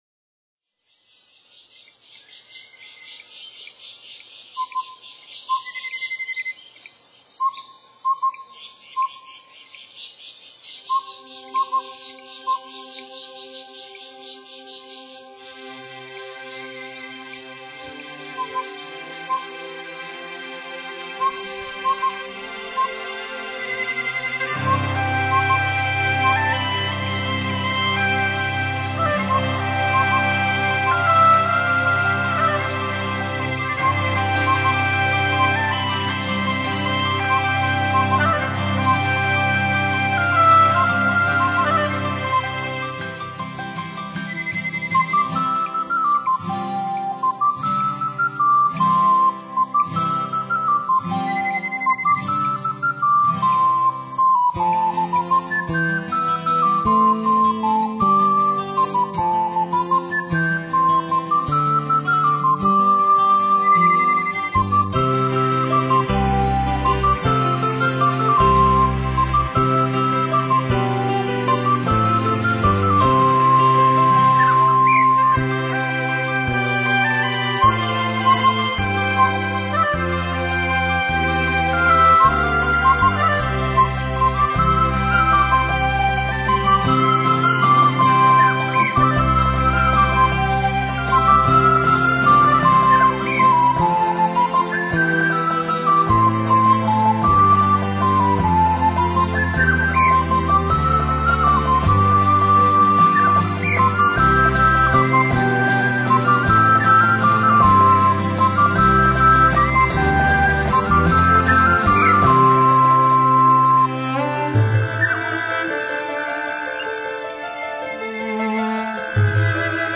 聽見大自然
100％褔山自然生態現場原音重現